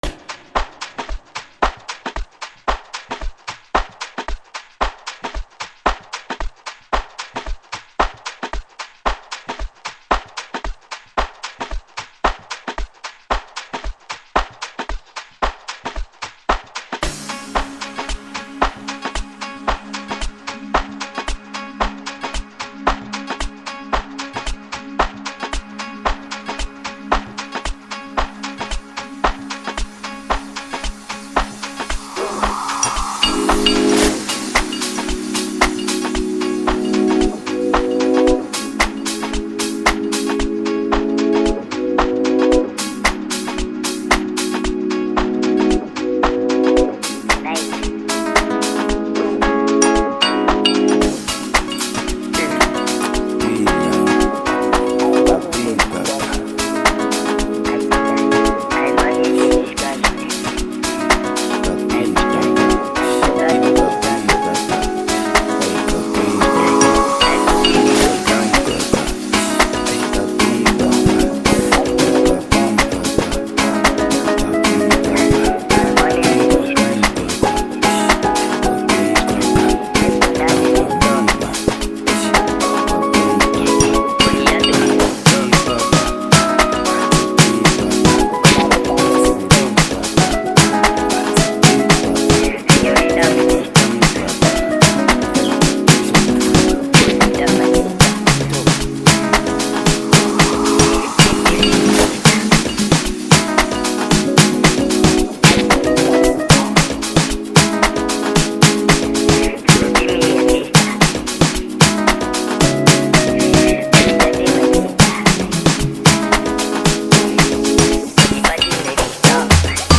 Amapiano track
guitar